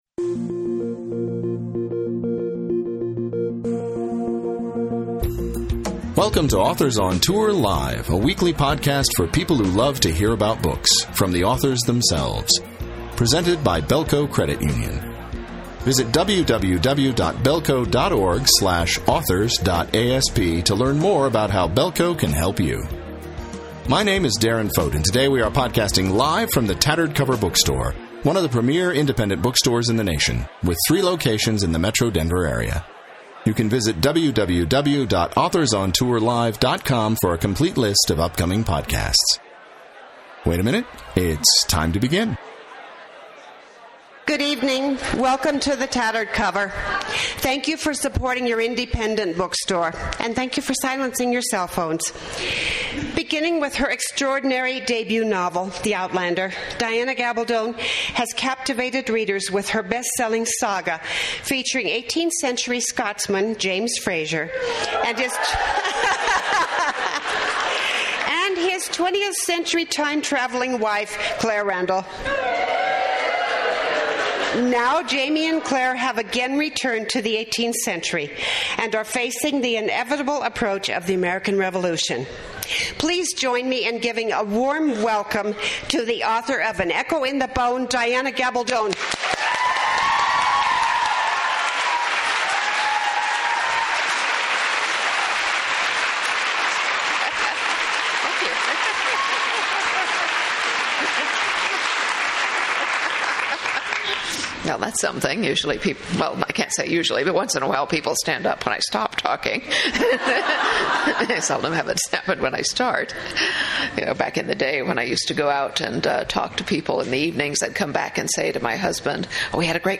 Gabaldon reads from and discusses An Echo in the Bone, the enormously anticipated seventh volume which continues the extraordinary story of the eighteenth-century Scotsman Jamie Fraser and his twentieth-century time-traveling wife, Claire Randall.